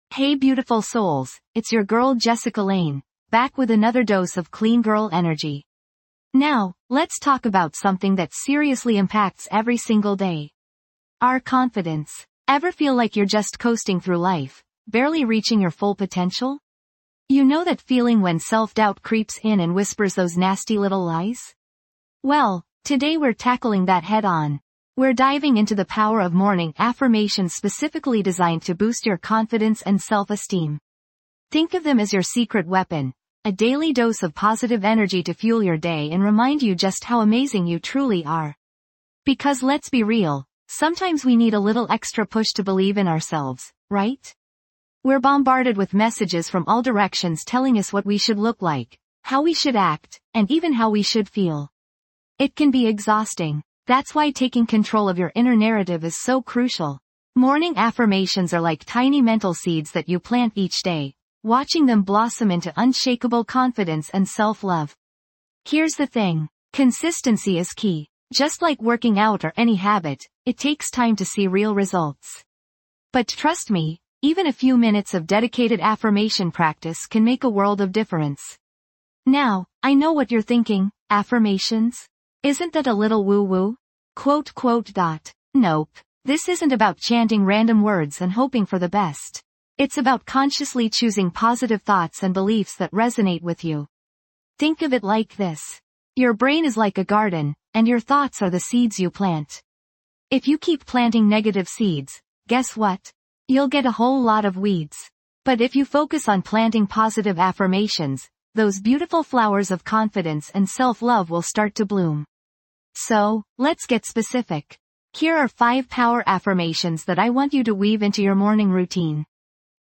Kickstart your day with confidence and self-esteem. Dive into a serene session of morning affirmations designed to empower your mind and uplift your spirit.
This podcast is created with the help of advanced AI to deliver thoughtful affirmations and positive messages just for you.